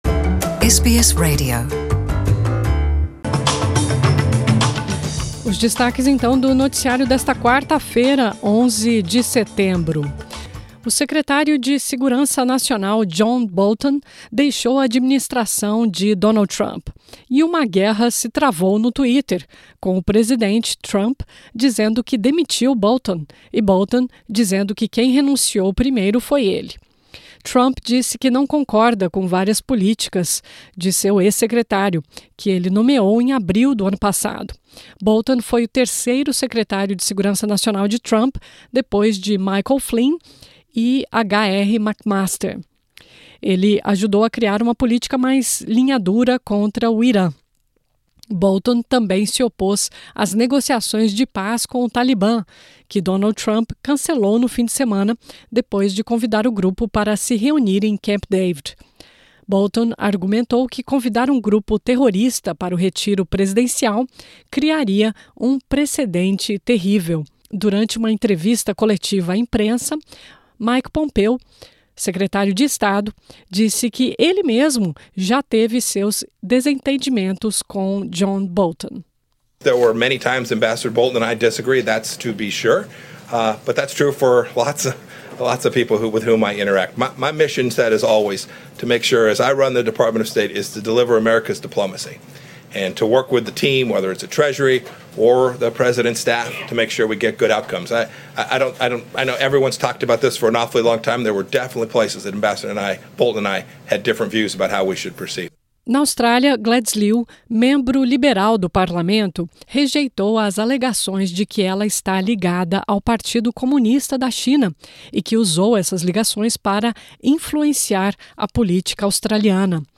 Ouça as notícias mais importantes do dia, em português.